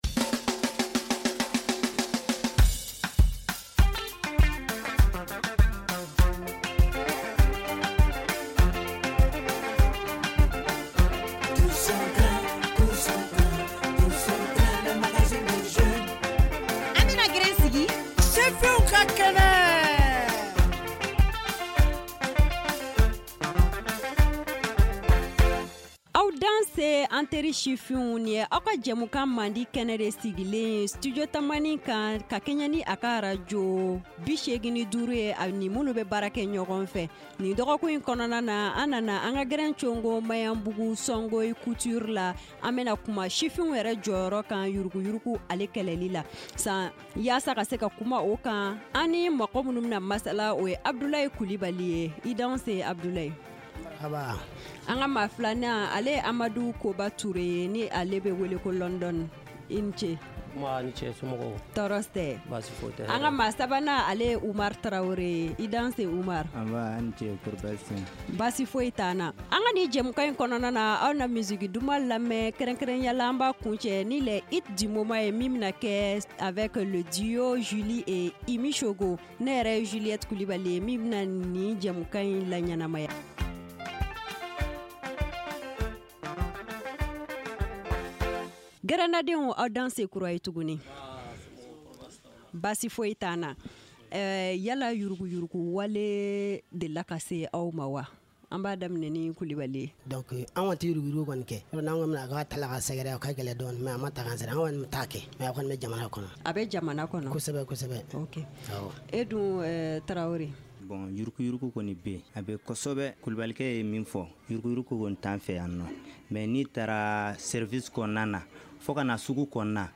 Voilà quelques questions auxquelles nos invités ont répondu dans ce numéro du « Tous au Grin » de Studio Tamani cette semaine à Magnambougou en commune VI de Bamako.